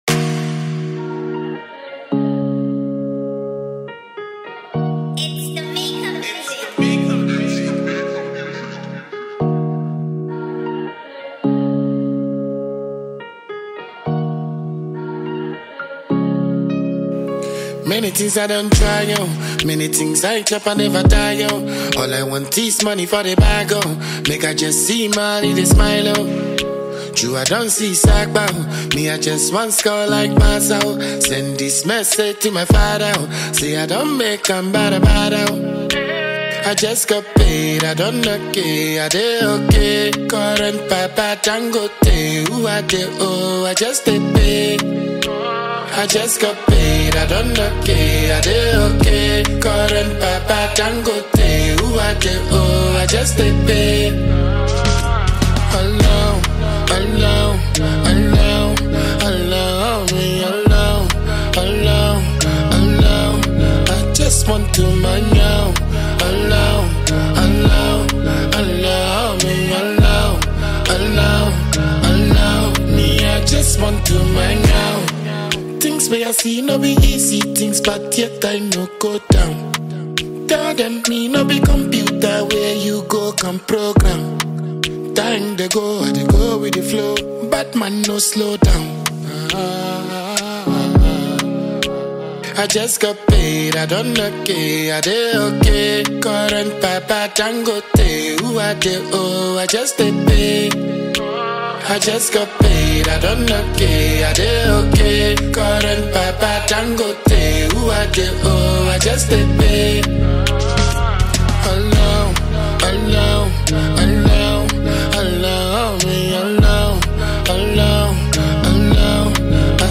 Ghanaian award-winning dancehall musician